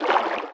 Sfx_creature_penguin_idlesea_A_04.ogg